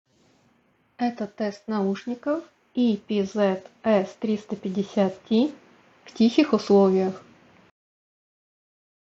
Качество передаваемого звука — среднее уровня.
В тихих условиях:
epz-s350t-tihie-uslovija.mp3